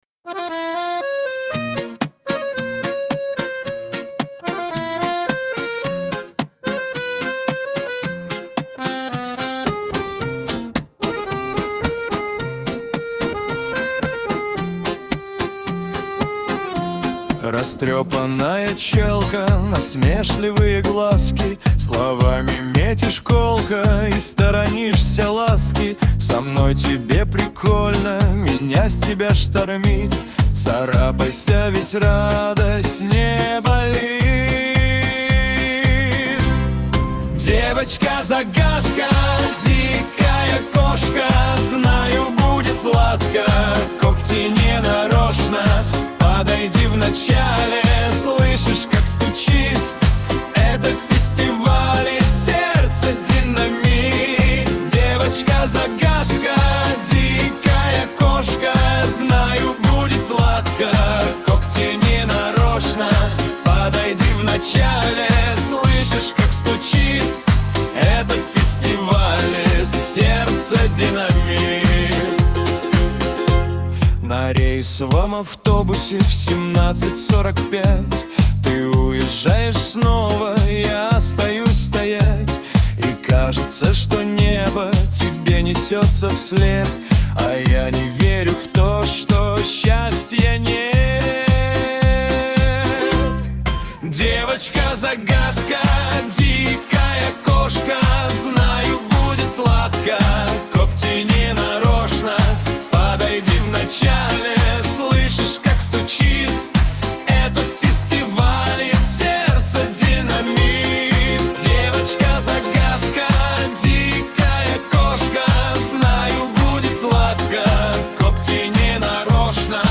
ХАУС-РЭП